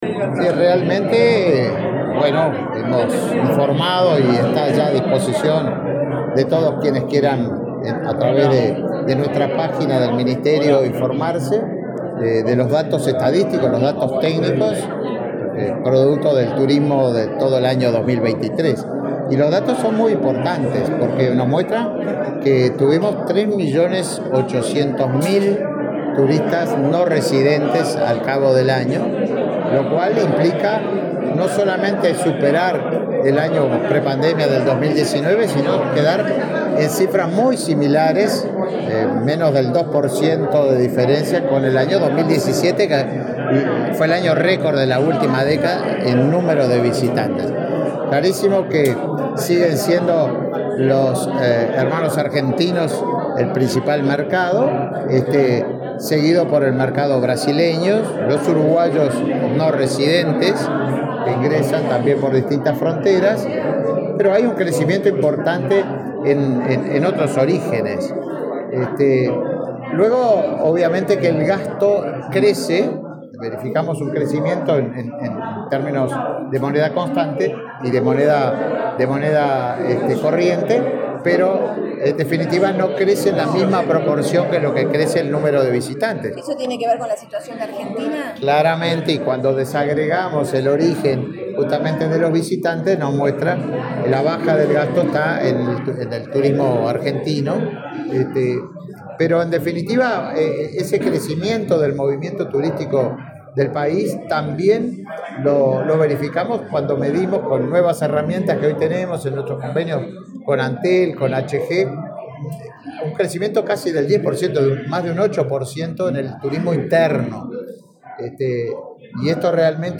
El ministro de Turismo, Tabaré Viera, dialogó con la prensa, luego de presentar datos del observatorio de turismo inteligente.